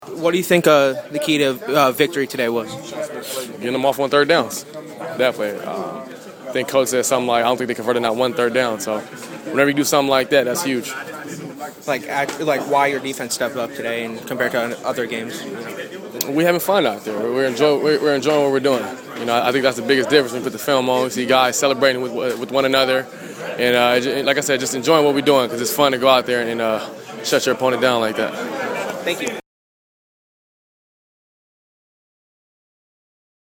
A bit before the two minute warning, I went downstairs to wait on line for access to the Dolphins’ and Bills’ locker rooms.
phins-sean-smith-locker-room.mp3